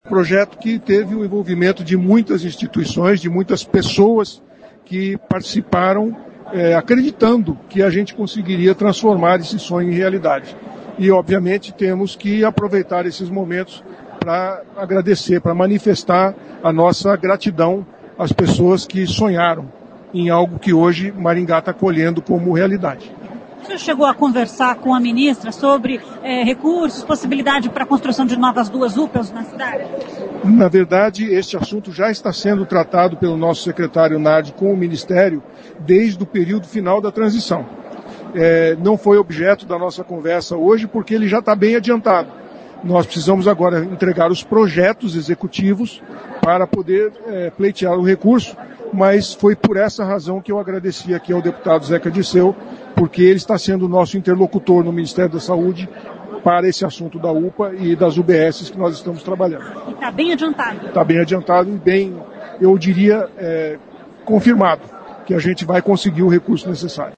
Em entrevista, durante a inauguração de leitos de UTI do Hospital da Criança, o prefeito Silvio Barros falou sobre o projeto de construção de duas novas unidades de pronto atendimento em Maringá, que foram promessas de campanha.